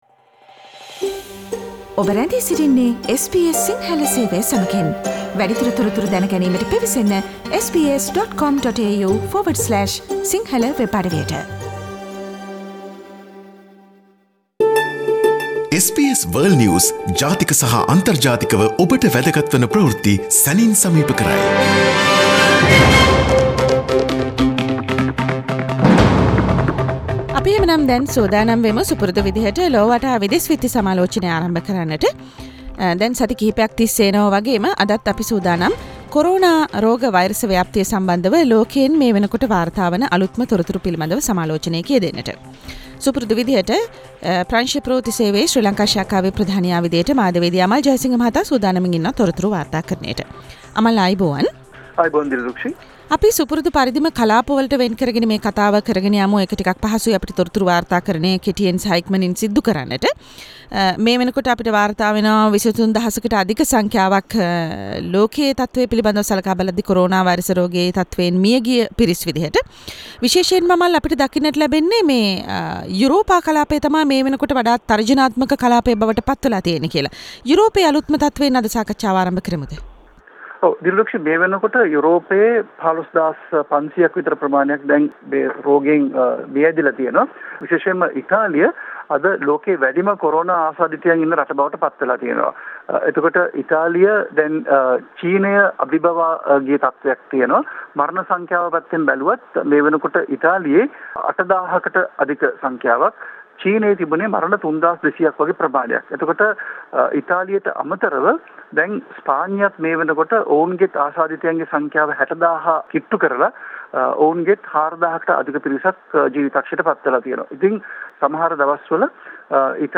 world news wrap - Source: SBS Sinhala radio